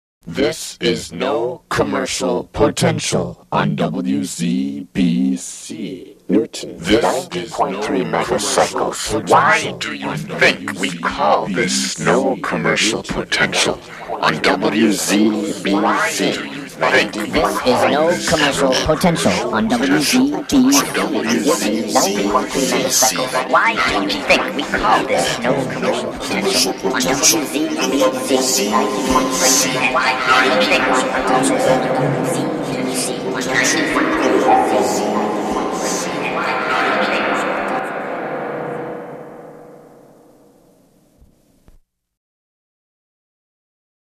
NCP ID 1982 Legal ID